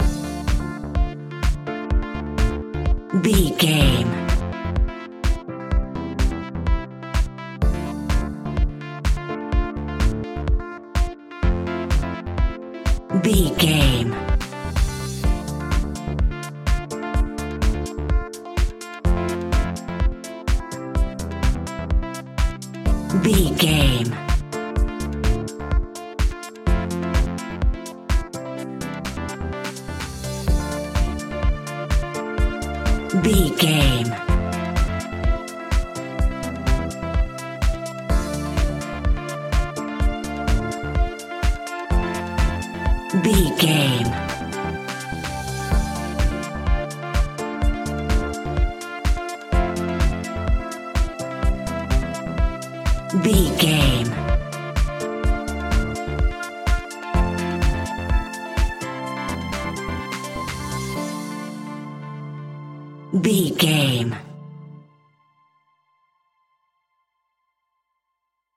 Aeolian/Minor
D
groovy
energetic
uplifting
hypnotic
drum machine
synthesiser
strings
funky house
deep house
nu disco
upbeat
funky guitar
synth bass